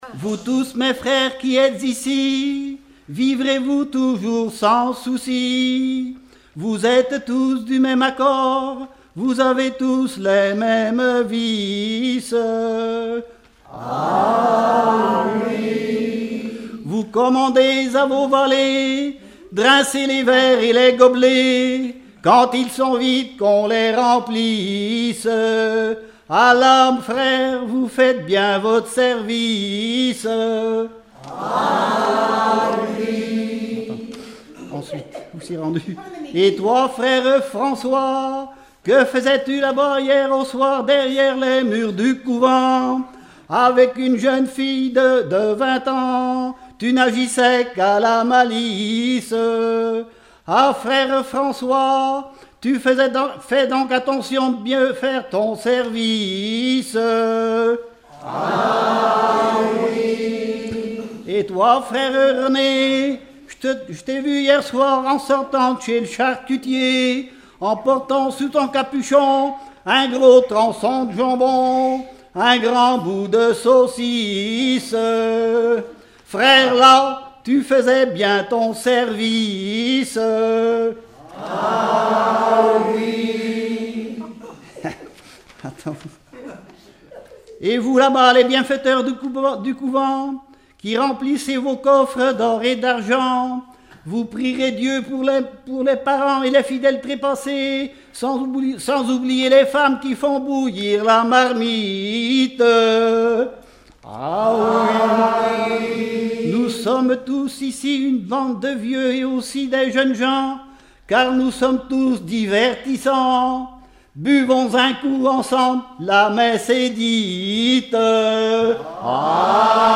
Regroupement de chanteurs du canton
Pièce musicale inédite